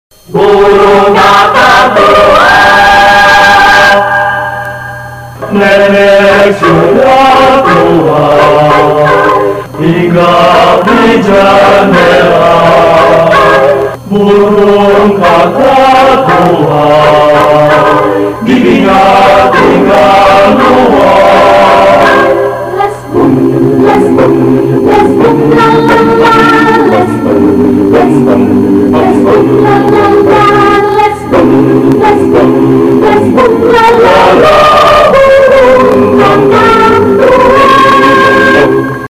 Repertoir - AUP Indonesian Chorale
Note: Sound quality may differ from original recording.